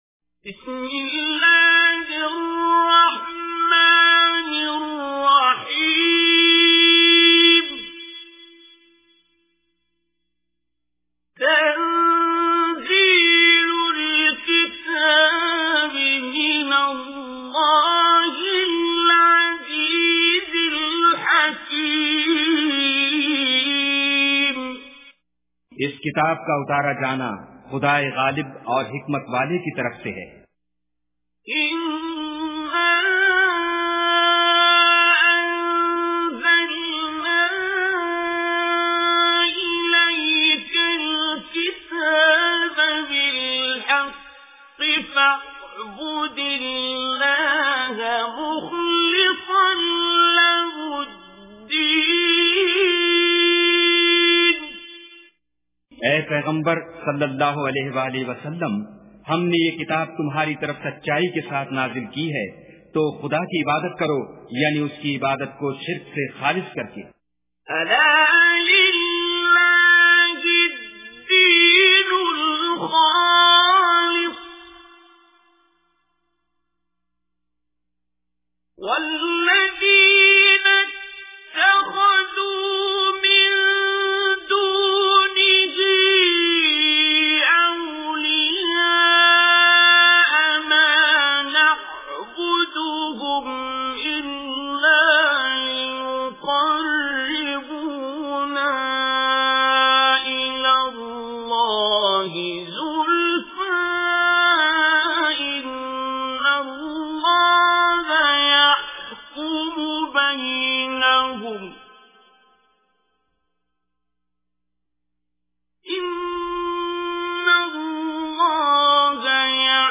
Surah Zumar Recitation with Urdu Translation
Surah Az-Zumar is 39th Surah or chapter of Holy Quran. Listen online and download mp3 tilawat / recitation of Surah Zumar in the voice of Qari Abdul Basit As Samad.
surah-zumar.mp3